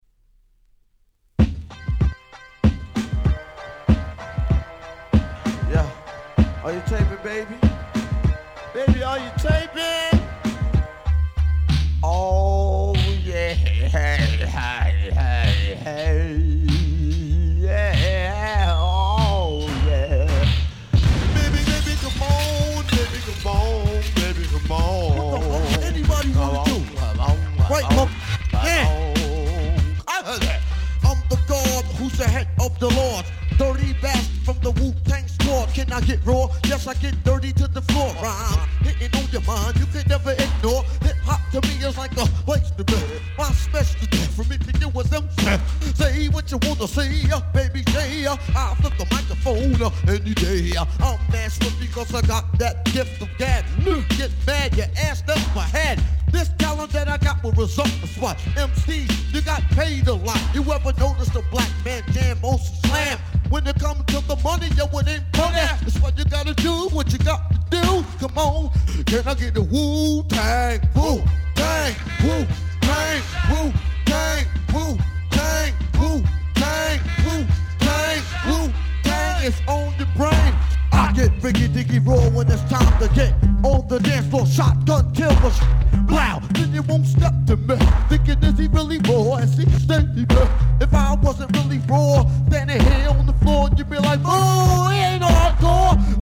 これぞ90's Hip Hop !!